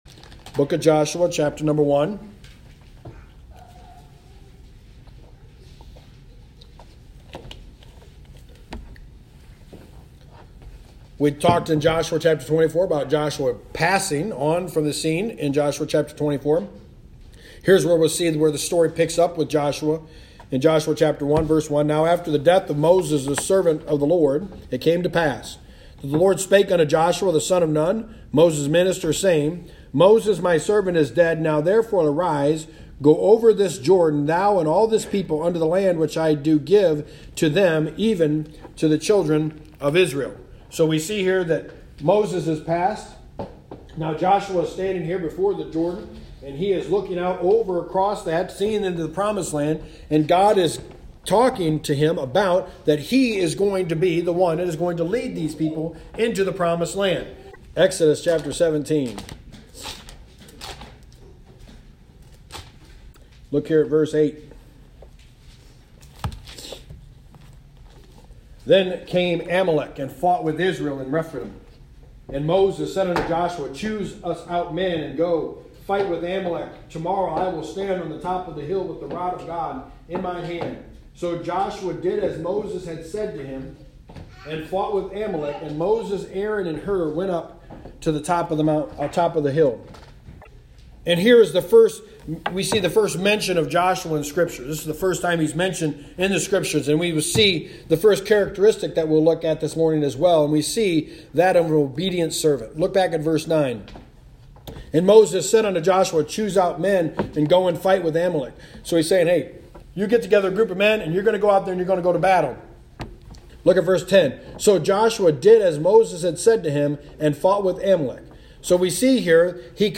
Service Type: Sunday Morning
The Book of Joshua: Sermon 1